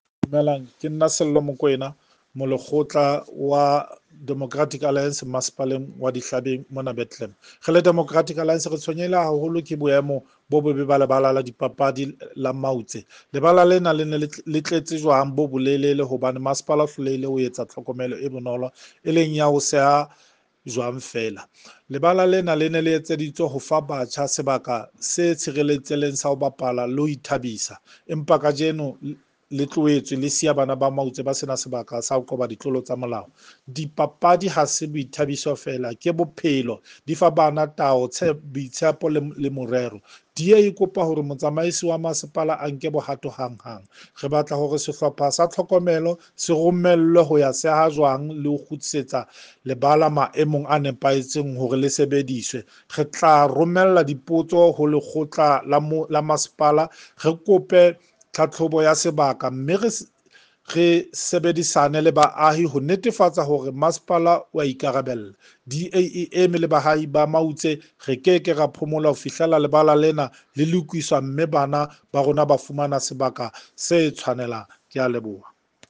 Sesotho soundbites by Cllr Sello Makoena.